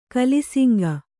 ♪ kalisiŋga